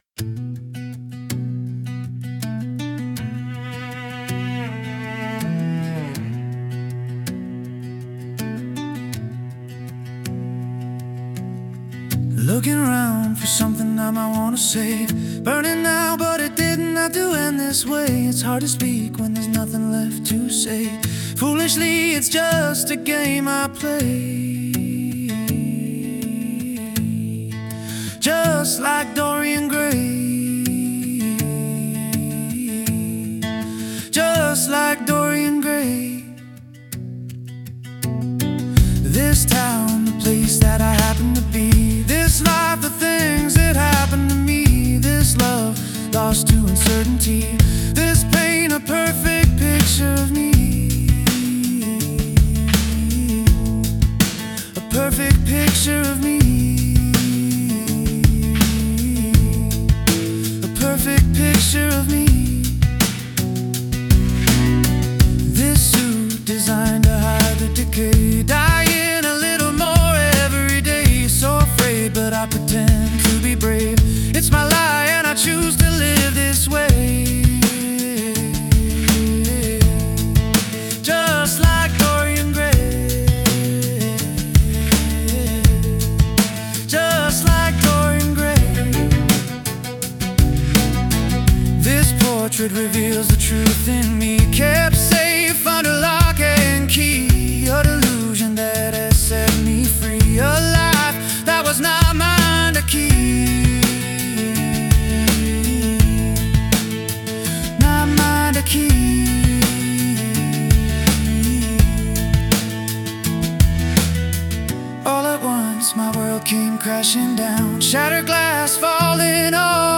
restrained and patient